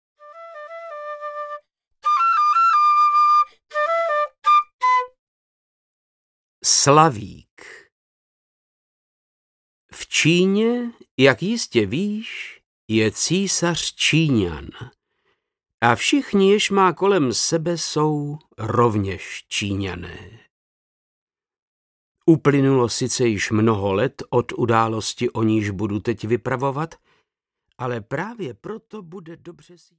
Slavík audiokniha
Ukázka z knihy
• InterpretVáclav Knop